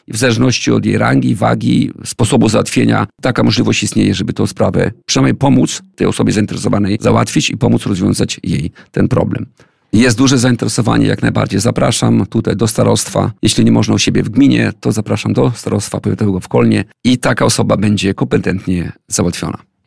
Starosta kolneński Tadeusz Klama na antenie Radia Nadzieja wyjaśniał, jak w praktyce działa udzielanie bezpłatnych porad.